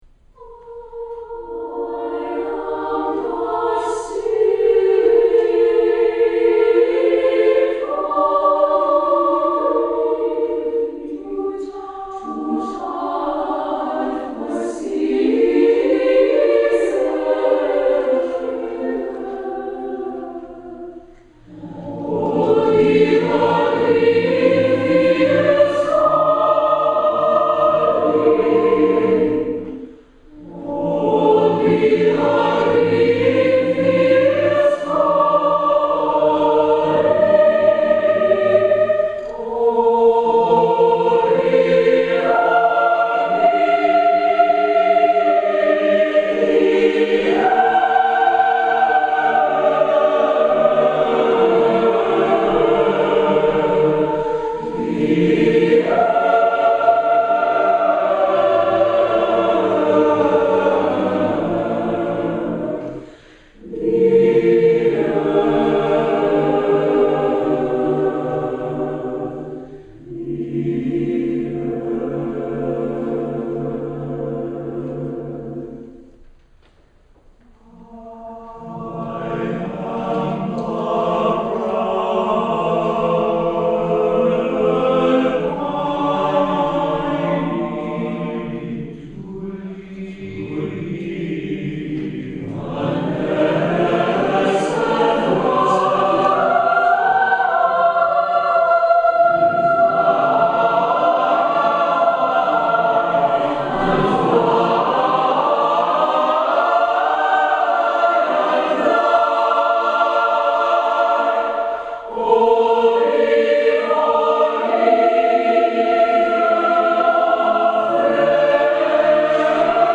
Voicing: SSATBB a cappella